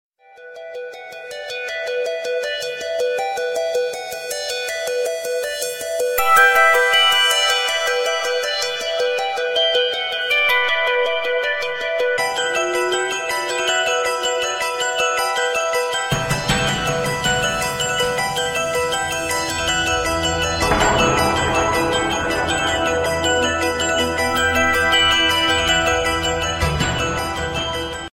surround-bells_24938.mp3